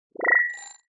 popup.wav